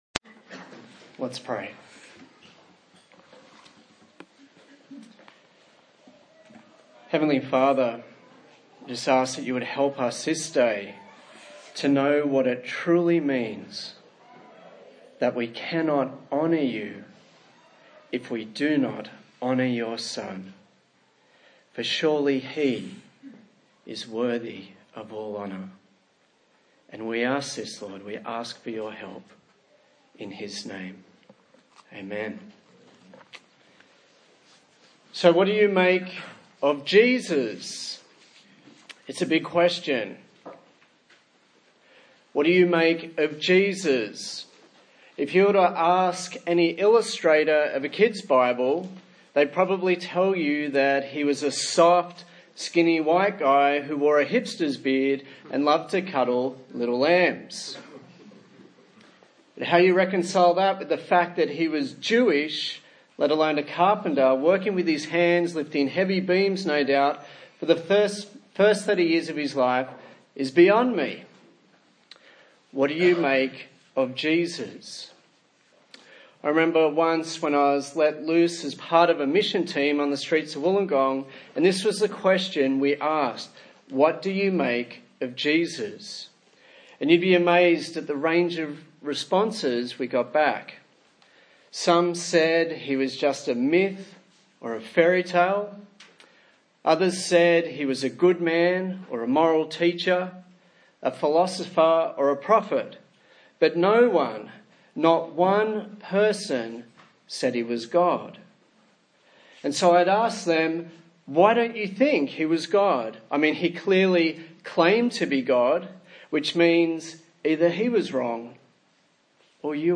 John Passage: John 5:1-30 Service Type: Sunday Morning